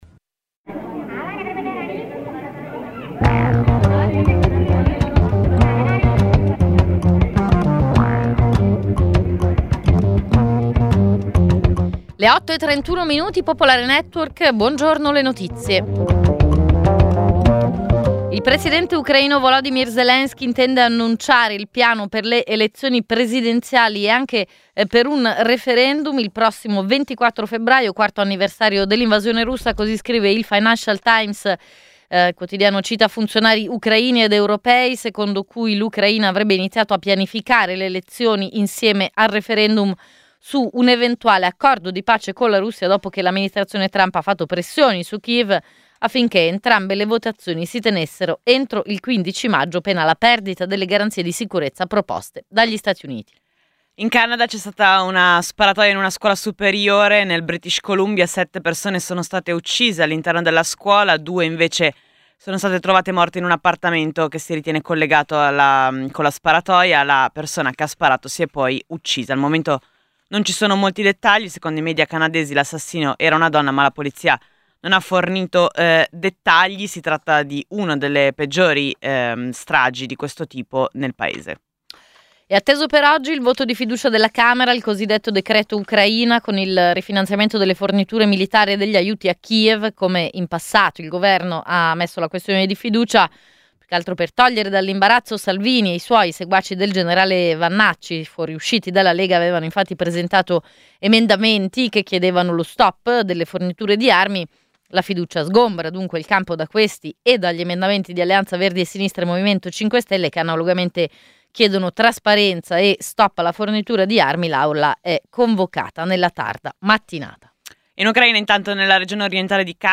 Edizione breve del notiziario di Radio Popolare. Le notizie. I protagonisti. Le opinioni. Le analisi.